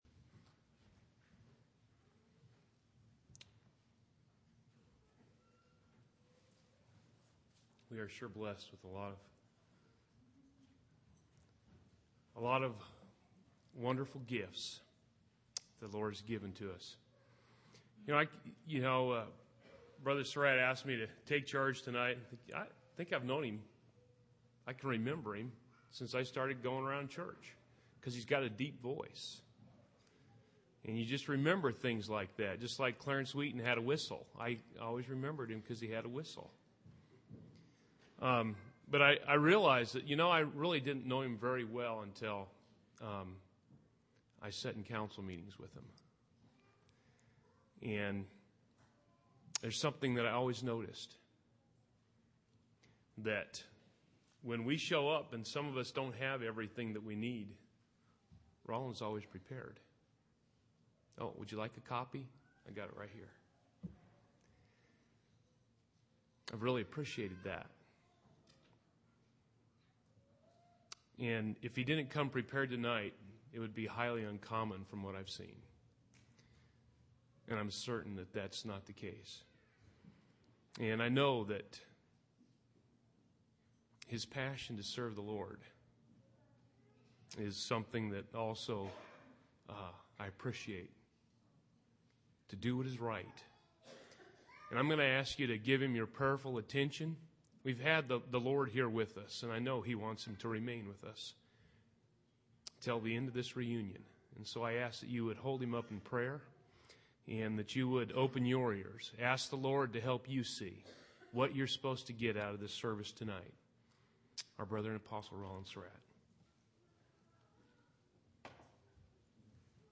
7/29/2007 Location: Missouri Reunion Event: Missouri Reunion